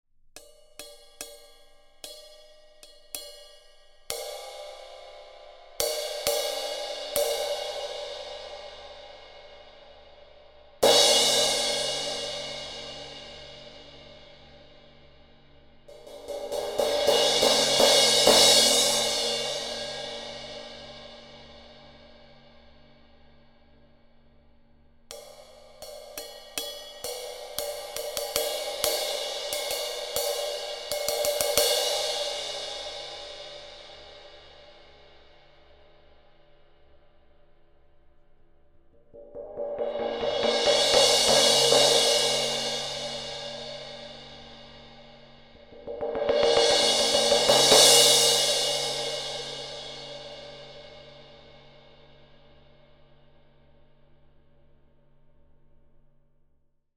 Combining stripes of alternating lathing and raw (unlathed) top surface and a fully lathed bottom, these cymbals combine the sounds of our popular Classic, Studio and Custom series all into one.
Anthem 18″ Crash Cymbal (approximate weight 1430 grams):